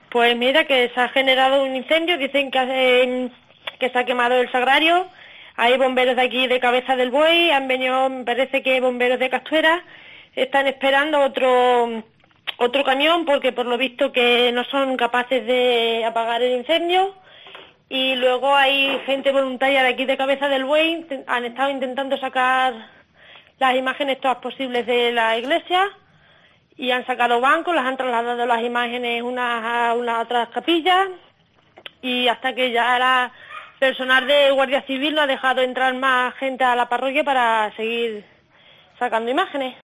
Vecina de Cabeza del Buey nos cuenta el suceso